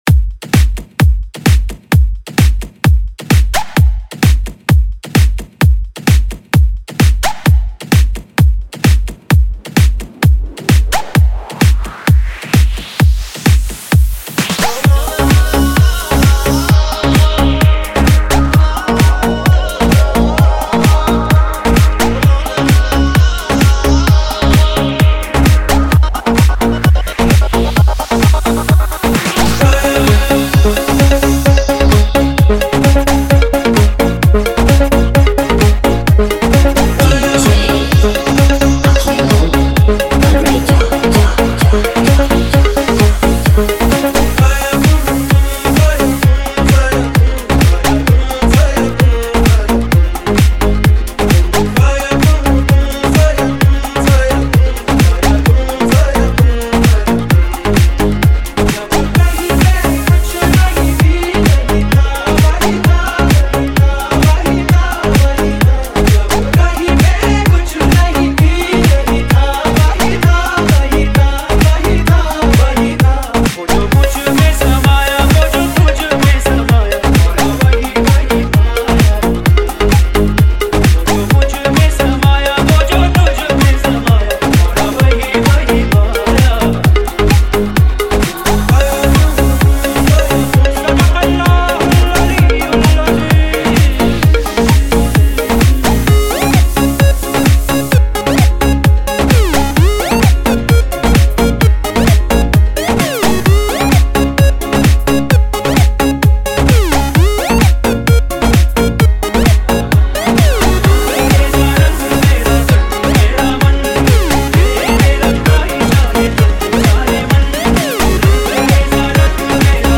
HomeMp3 Audio Songs > Others > Single Dj Mixes